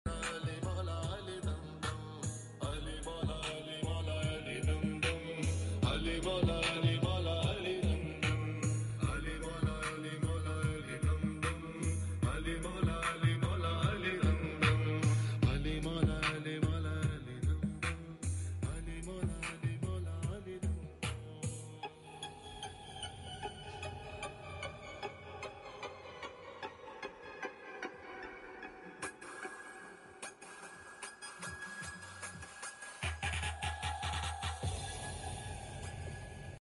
8 inches woofer speaker with Amplifier